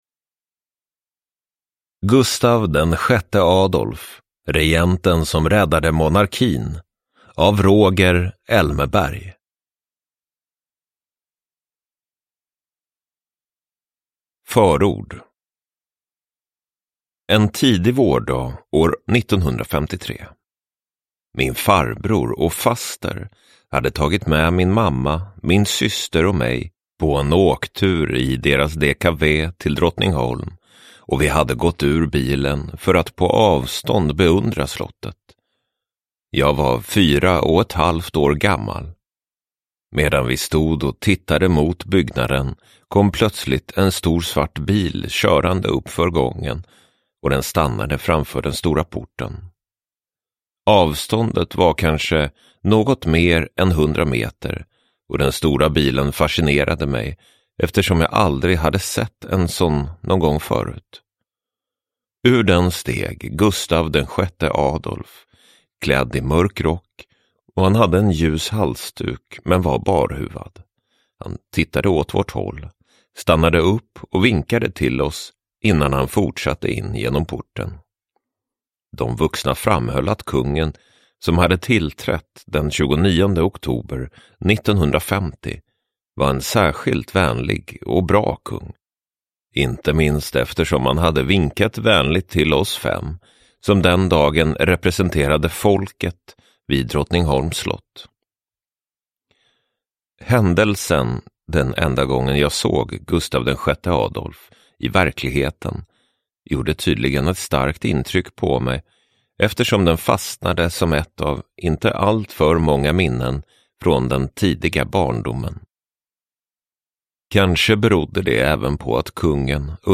Gustaf VI Adolf : regenten som räddade monarkin – Ljudbok – Laddas ner